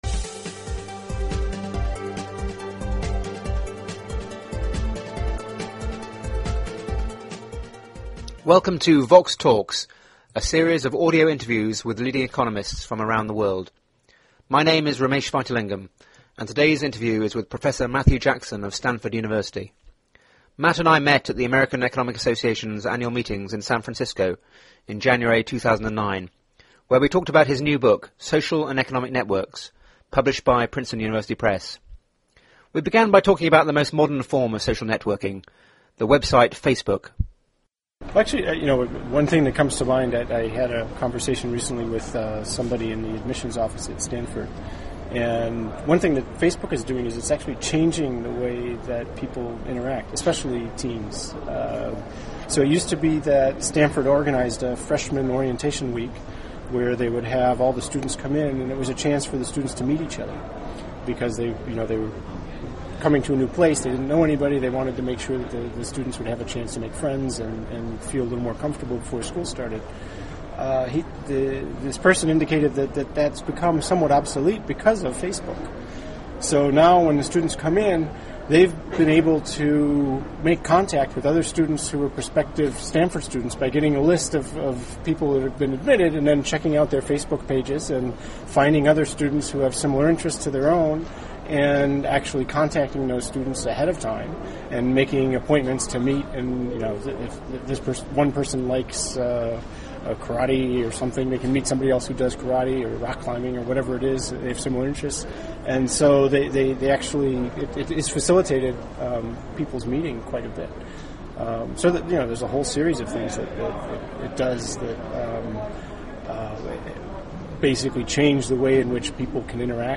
They discuss the impact of communication technologies like Facebook on social and professional relationships; how social connections interact with financial transactions in settings from western banks to rural India; and the potentially negative effects of networks on social fragmentation. The interview was recorded at the American Economic Association meetings in San Francisco in January 2009.